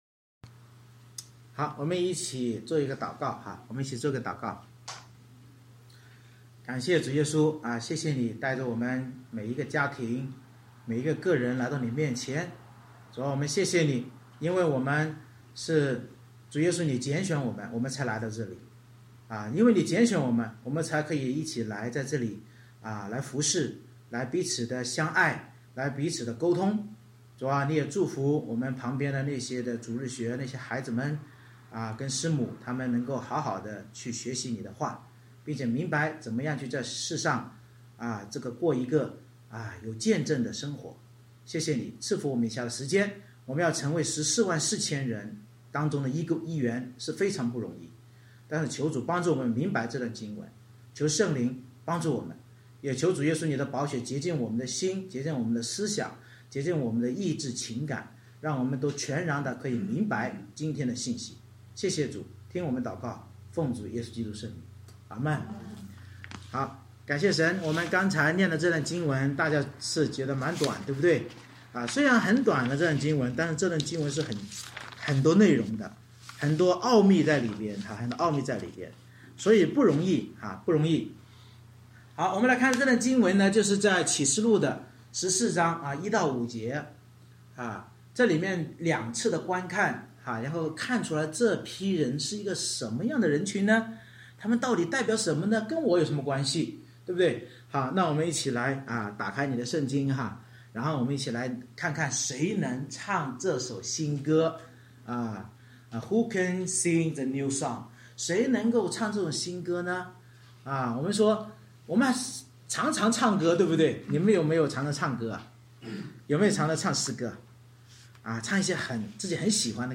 Series: 《启示录》讲道系列 Passage: 启示录14:1-5 Service Type: 主日崇拜 除了羔羊基督用宝血买赎回来的那十四万四千忍耐与信心之得胜者，没有人能在神与羔羊宝座面前学唱这首新歌。